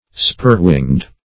Spur-winged \Spur"-winged`\ (-w[i^]ngd`), a. (Zool.)